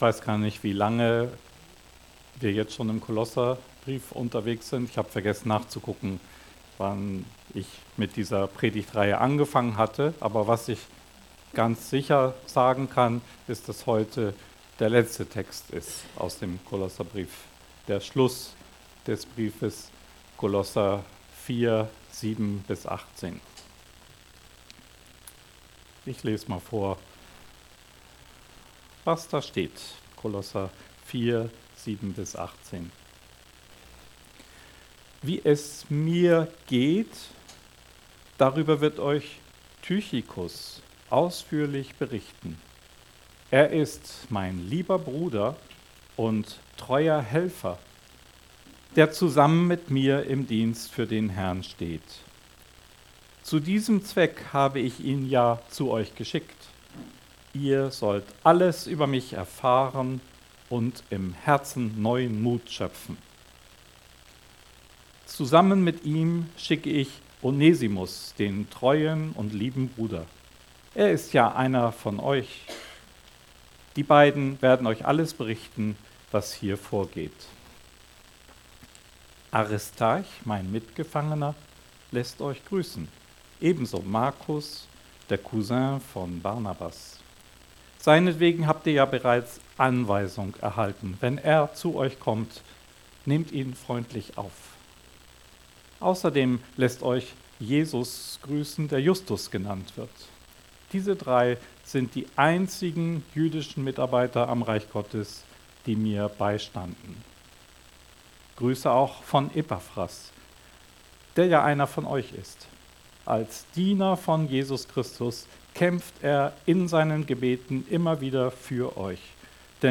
Predigtserie